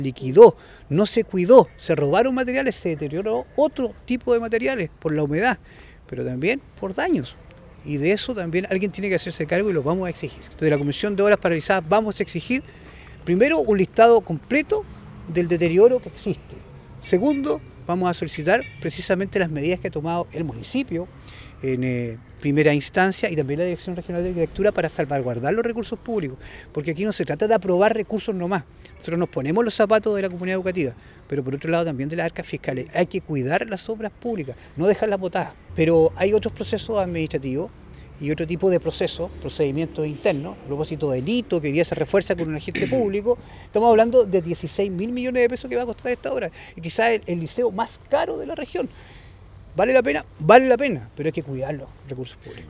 Muchos de los materiales fueron robados y otros resultaron deteriorados por la humedad o falta de cuidados, por lo que el Consejero Reyes señaló que se debe ser responsable con los recursos públicos.